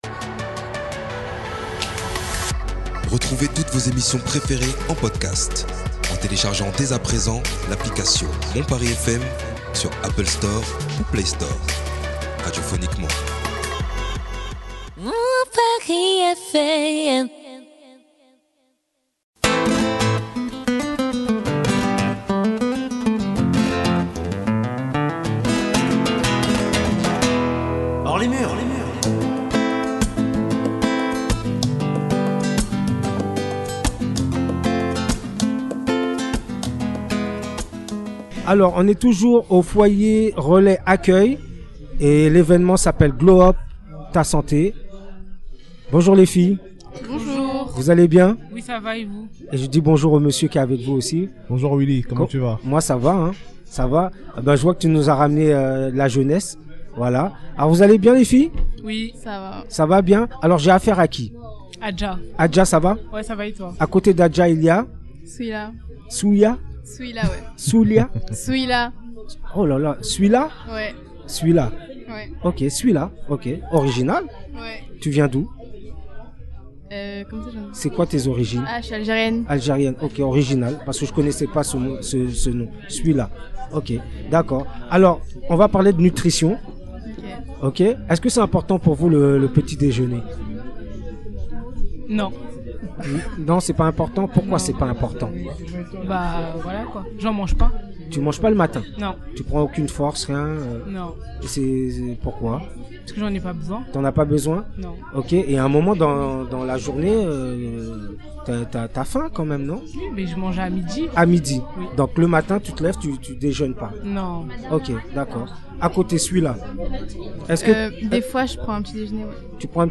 se sont rendus au foyer relais acceuil dans le 13eme arrondissement de Paris pour interviewer les intervenants et jeunes participant à l'evenement Glow Up ta Santé #2